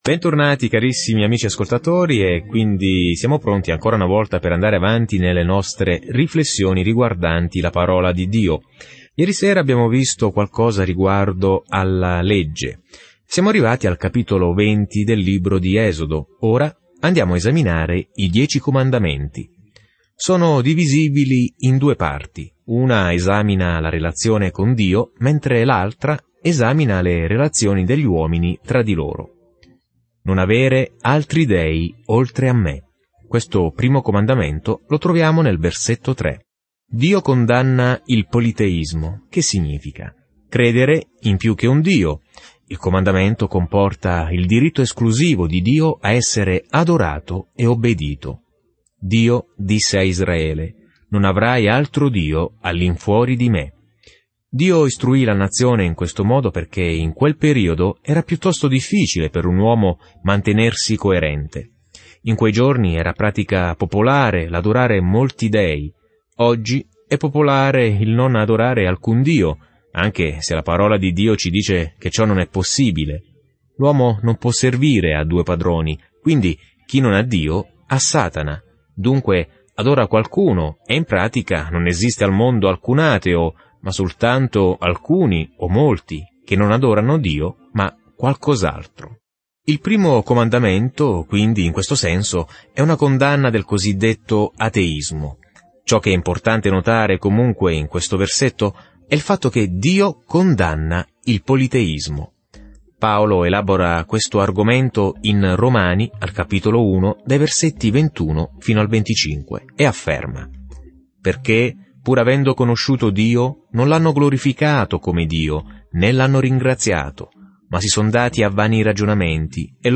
Scrittura Esodo 20:3-13 Giorno 22 Inizia questo Piano Giorno 24 Riguardo questo Piano L'Esodo ripercorre la fuga di Israele dalla schiavitù in Egitto e descrive tutto ciò che accadde lungo il percorso. Viaggia ogni giorno attraverso l'Esodo mentre ascolti lo studio audio e leggi versetti selezionati della parola di Dio.